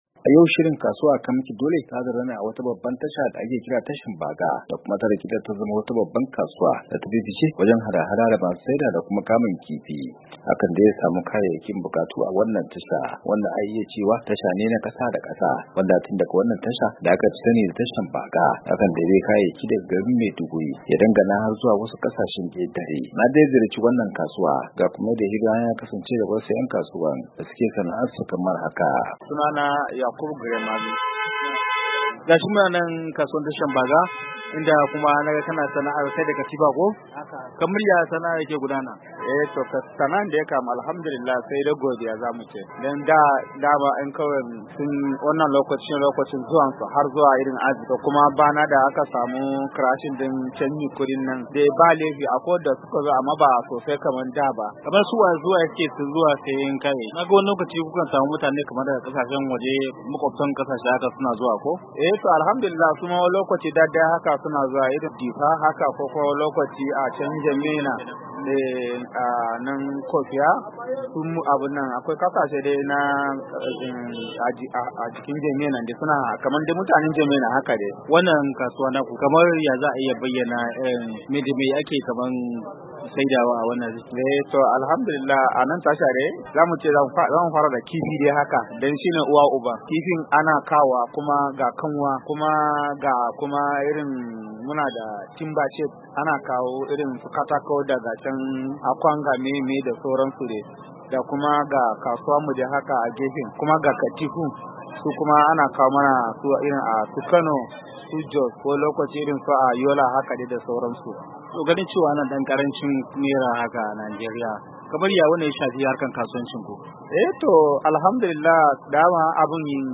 Shirin Kasuwa a kai maki dole na wannan makon ya kai ziyara Tashar Baga, wacce ta rikide ta koma kasuwar hada-hadar kifi da sauran wasu kayayyaki.
'Yan kasuwar sun yi bayani kan harkar kasuwancinsu da kuma yadda sauya fasalin Naira da gwamnatin Najeriya ta yi ya shafi harkokin cinikayyarsu sosai. Bayan haka 'yan kasuwar sun yi fatan a yi zaben gwamoni a Najeriya a gama lafiya.